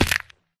should be correct audio levels.
fallbig2.ogg